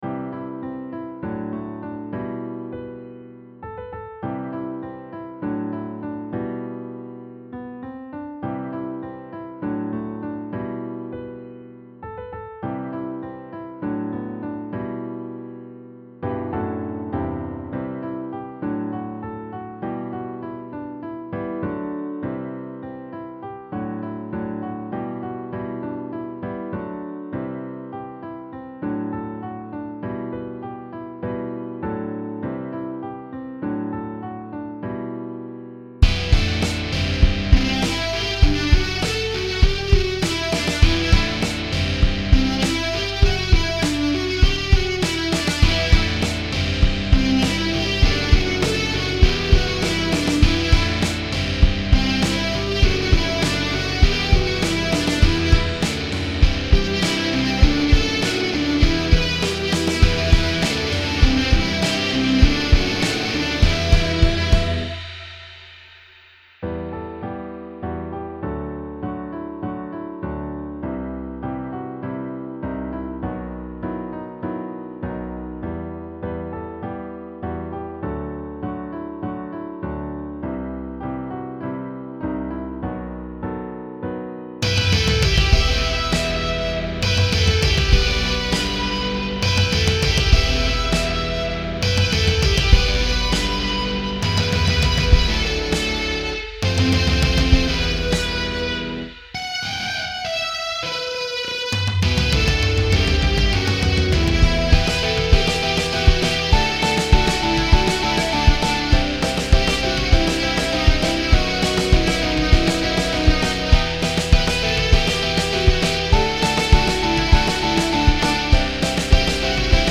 HardRock
The combination of piano and distorted guitar.